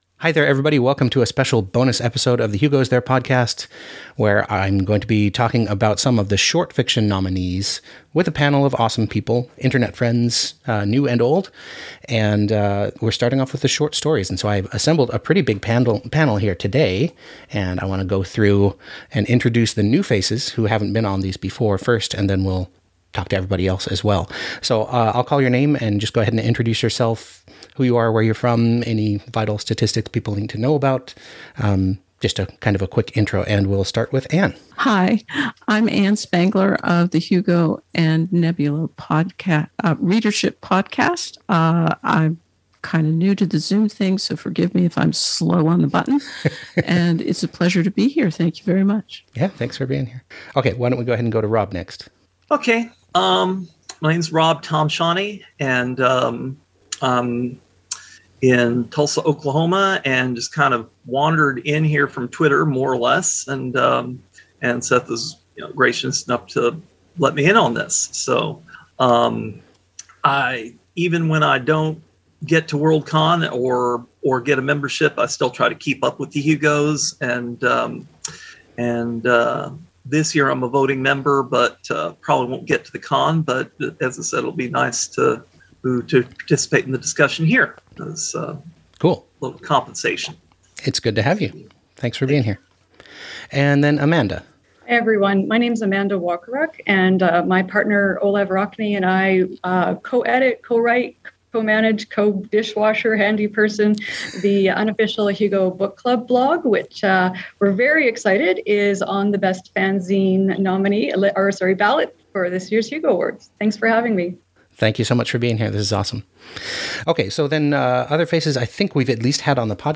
This is the audio-only version of a Zoom discussion panel about the 2022 Hugo nominees for Best Short Story.
2022-hugo-nominees-for-short-story-discussion-panel.mp3